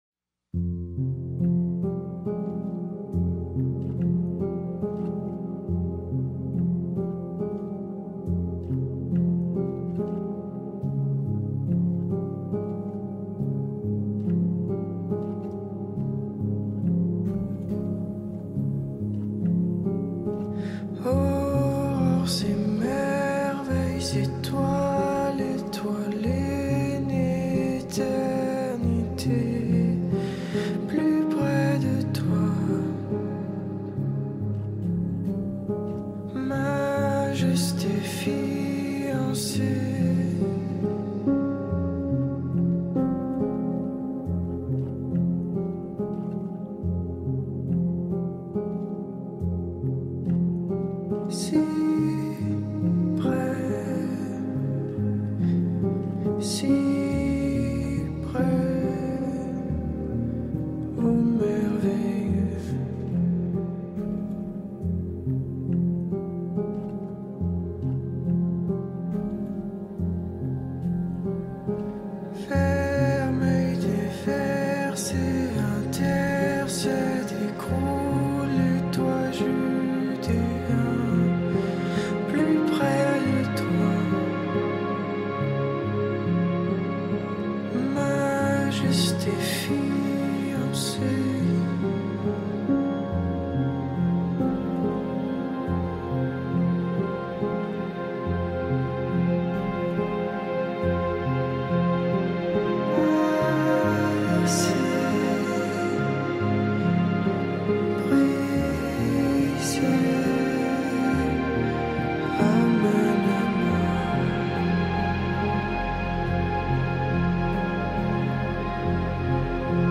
french music